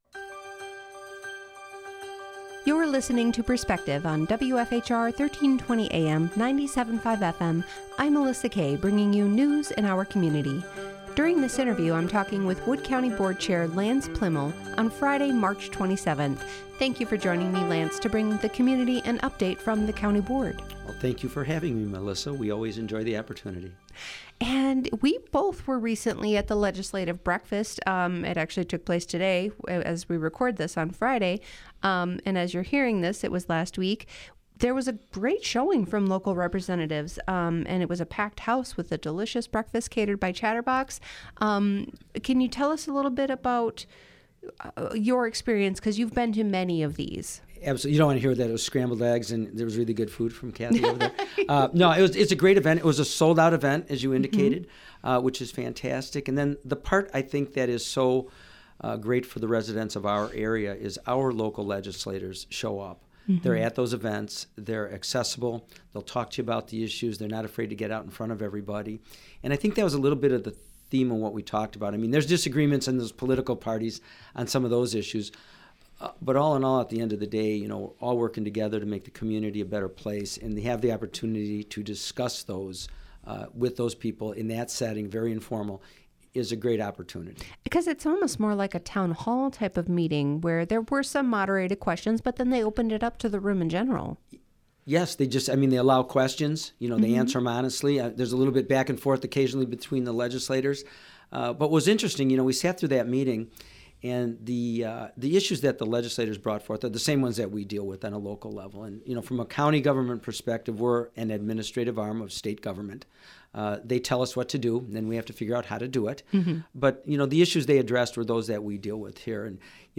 Wood County Board Chair Lance Pliml